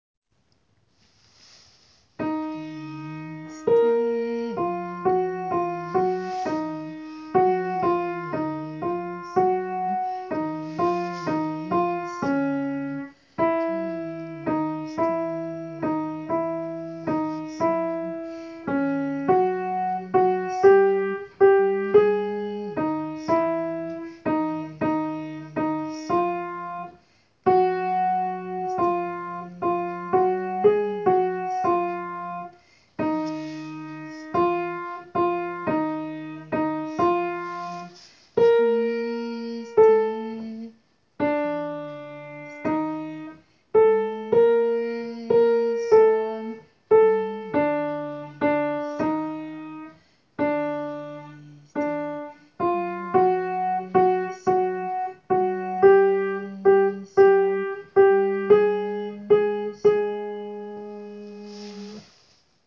Alto
gounod.christe.alto_.wav